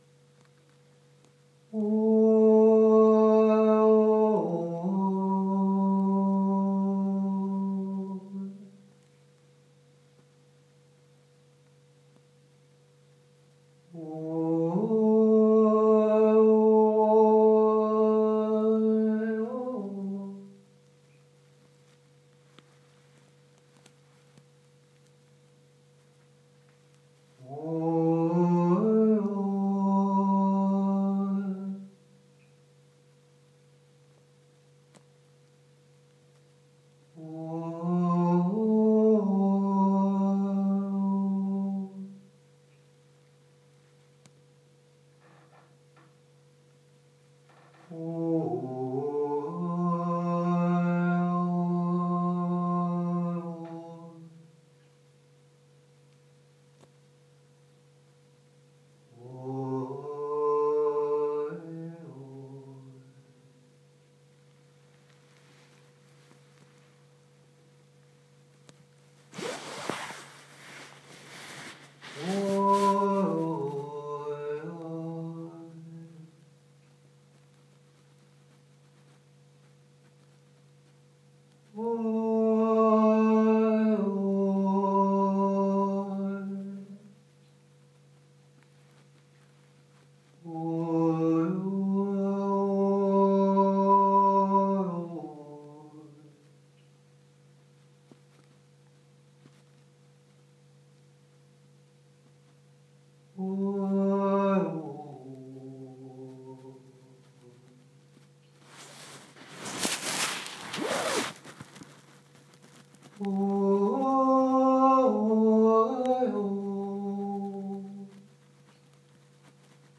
Satt i går (slik tilfelle av og til er) i labben og sang med morilden. Jeg fikk det for meg at responsen fra morilden økte når jeg sang med en tone som var overtonerik.
Morilden skimrer til i pausene mellom de korte strofene jeg synger.
morildovertonerikt.m4a